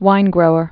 (wīngrōər)